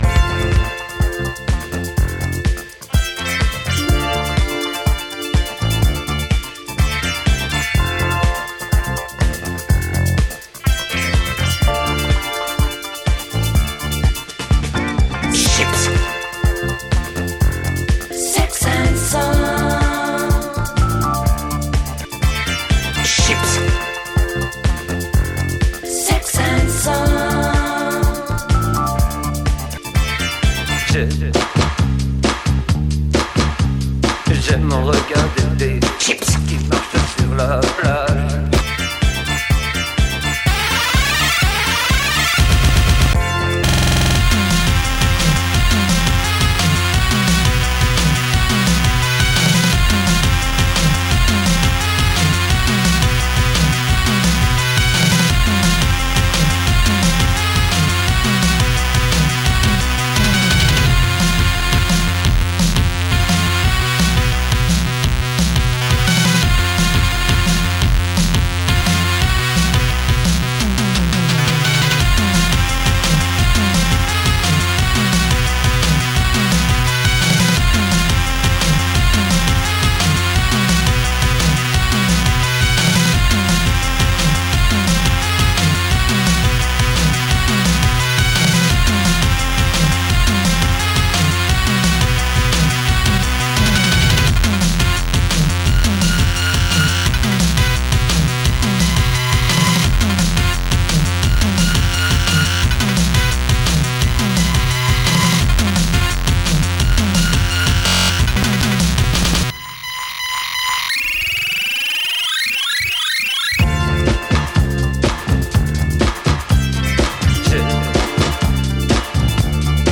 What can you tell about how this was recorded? depuis la flottille pour Gaza